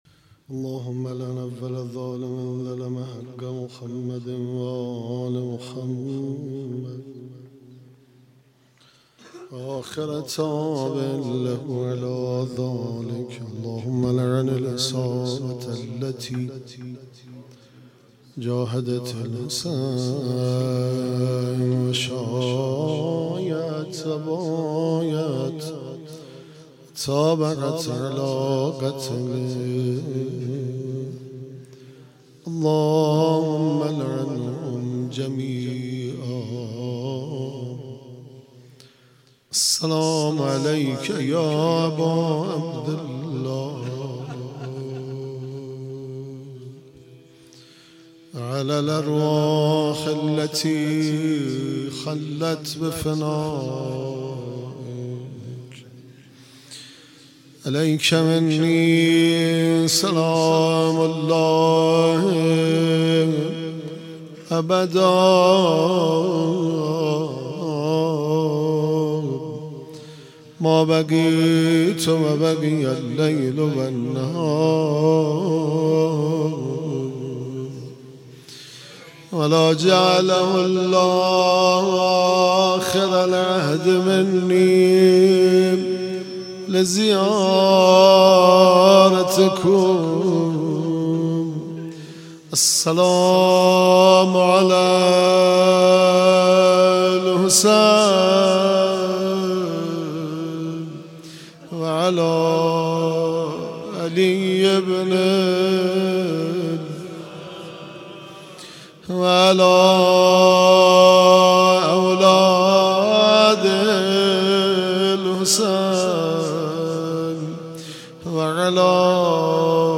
مرثیه سرایی